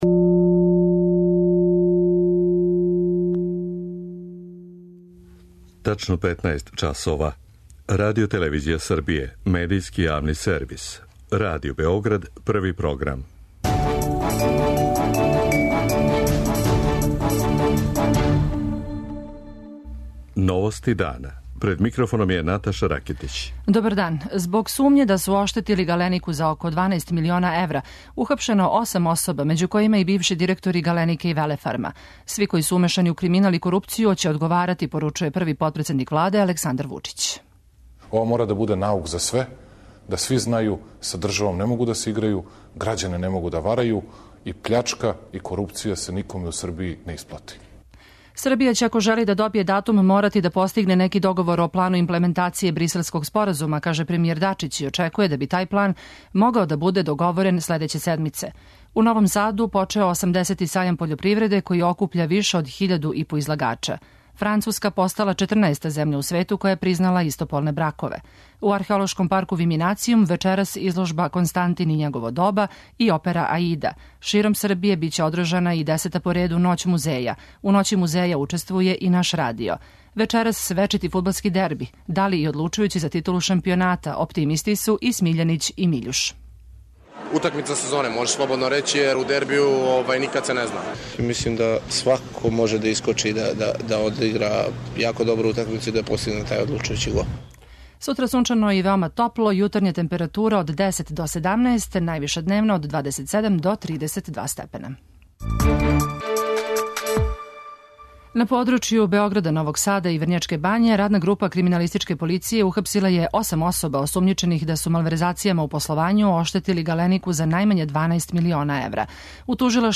Земља партнер ове године је Хрватска, а на Новосадском сајму је и наш новинар.